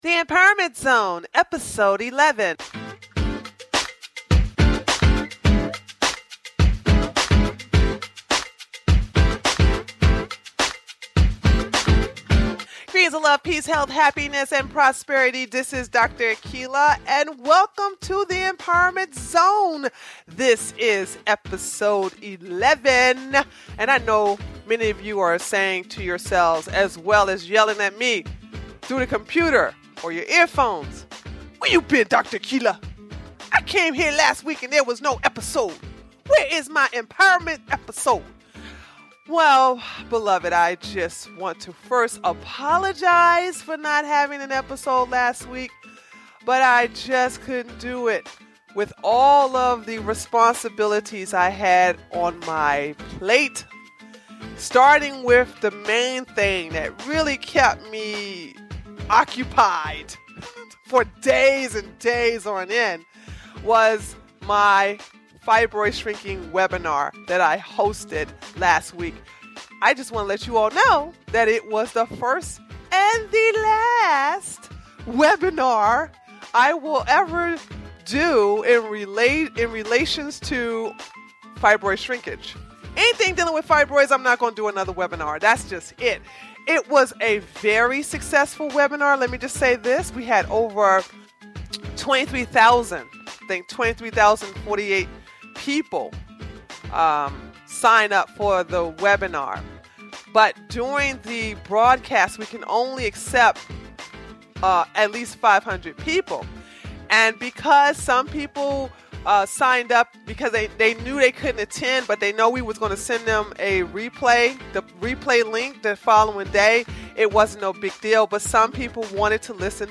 I include voice questions as well as written questions submitted by listeners and I answer them individually.